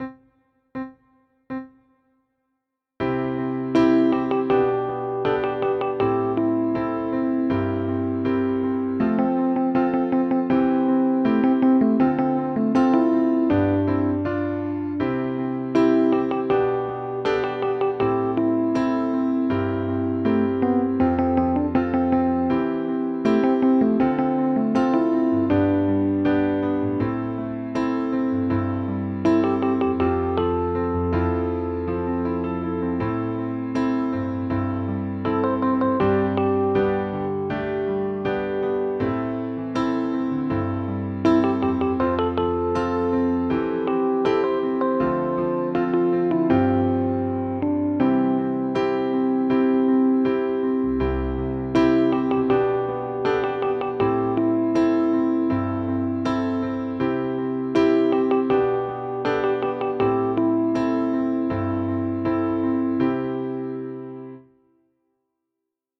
コードは勝手に変えましたが、フルサイズだとこんな感じです（音が出ます）